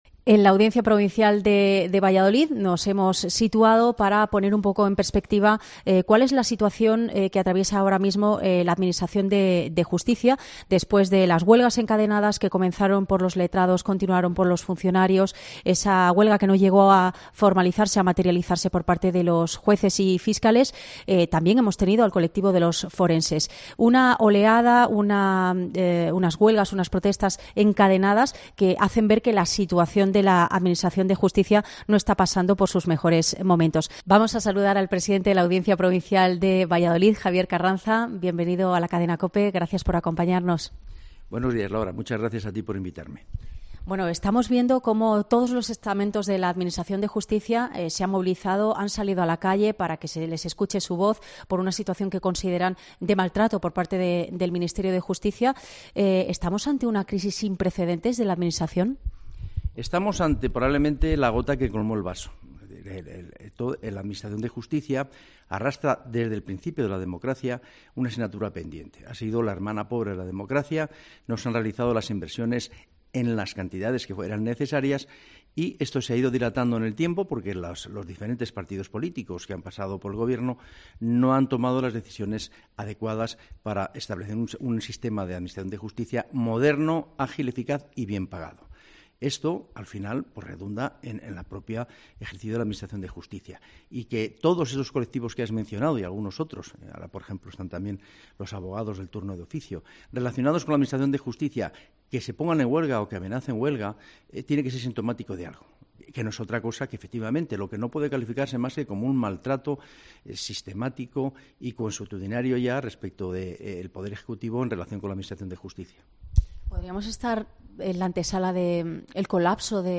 El presidente de la Audiencia de Valladolid analiza el estado de salud de la Justicia en Herrera en COPE